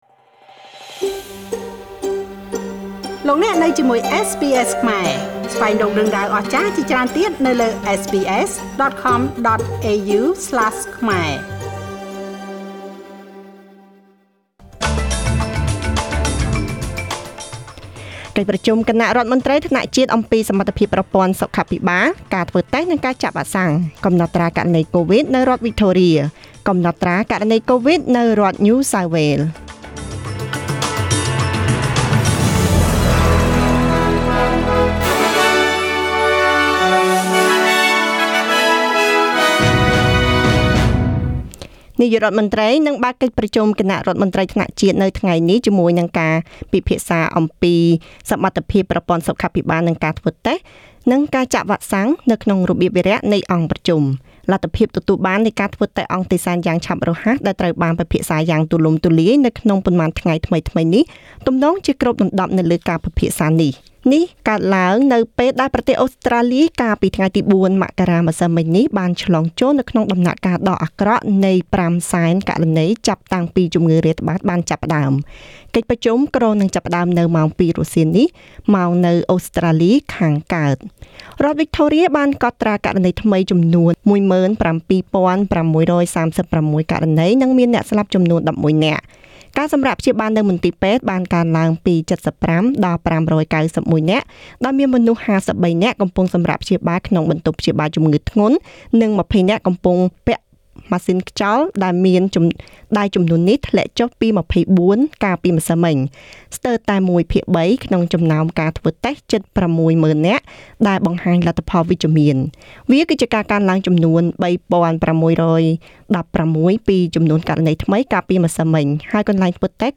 នាទីព័ត៌មានរបស់ SBS ខ្មែរ សម្រាប់ថ្ងៃពុធ ទី៥ ខែមករា ឆ្នាំ២០២២